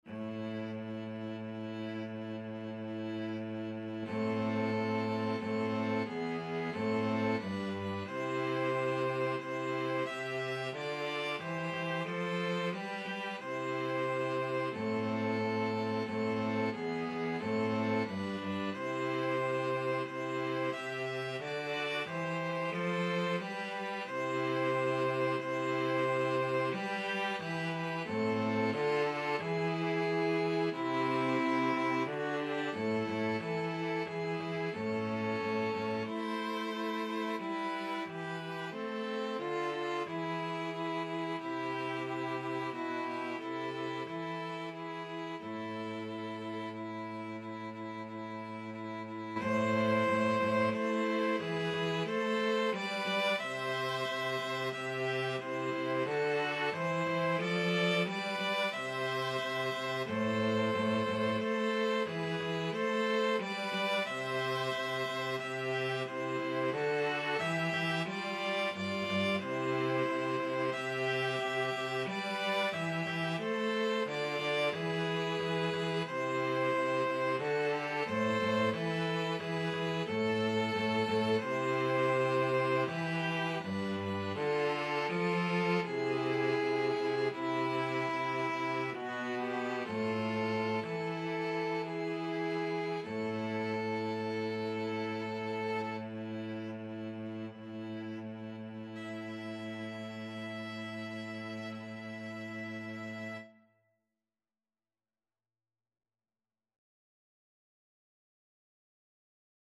String trio version
=90 Allegretto, ma un poco lento
Classical (View more Classical String trio Music)